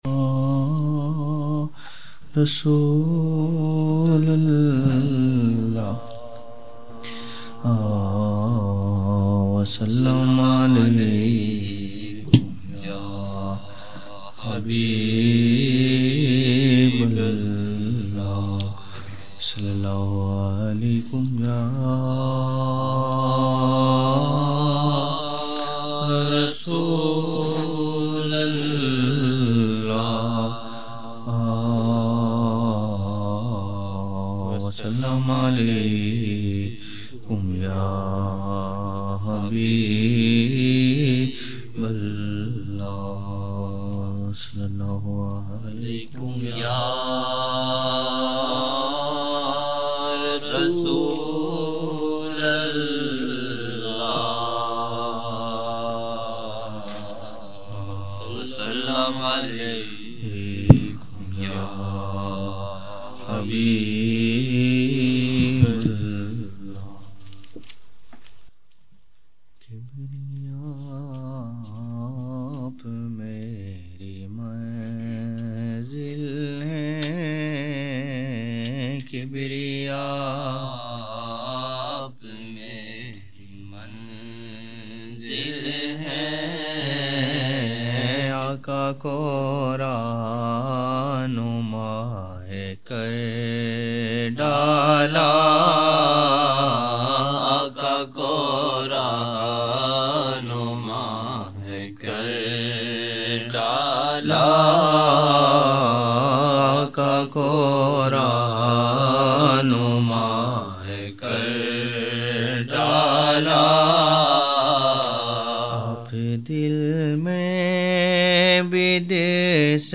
12 November 1999 - Fajr mehfil (4 Shaban 1420)